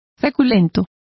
Complete with pronunciation of the translation of starchy.